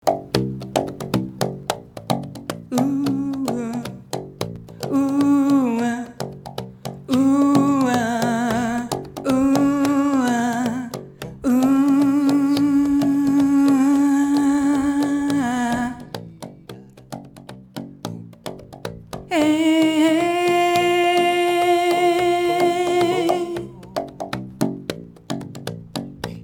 Tauche ein in die faszinierende Welt der Klangskulpturen.
Entdecke hörend das Klangspiel verschiedener Materialien.